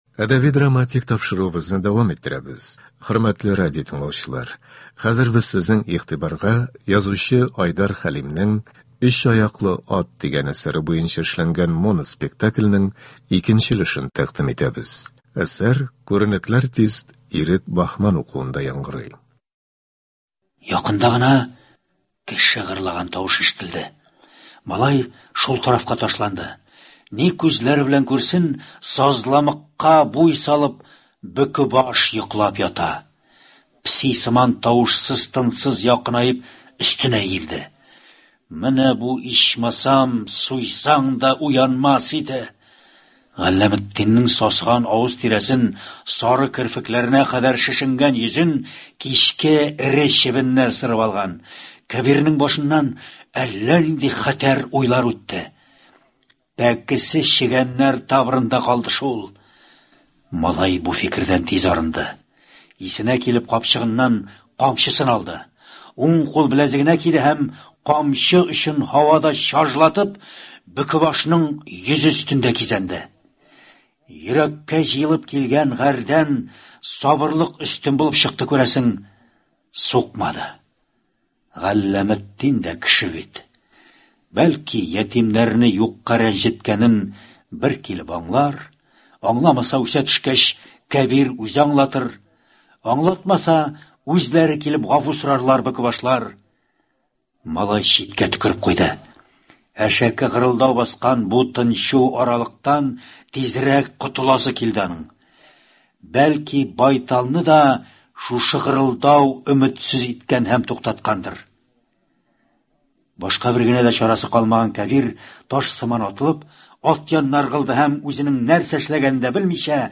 Моноспектакль.